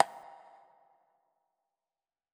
heartbeat.wav